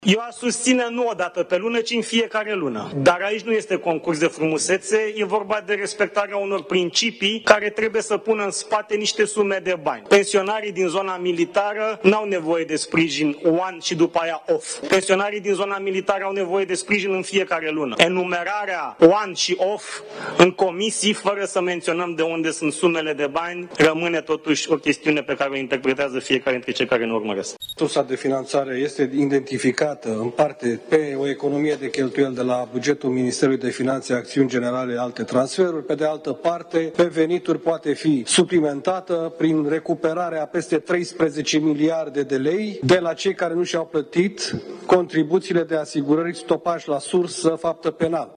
La ședința reunită de buget și finanțe, ministrul Apărării, Radu Miruță, a motivat că nu sunt bani pentru aceste excepții, iar din partea PSD, deputatul Adrian Câciu spune că ar fi venituri la sume nerecuperate de stat.
Ministrul Apărării, Radu Miruță: „Pensionarii din zona militară n-au nevoie de sprijin «one» și după aia «off»”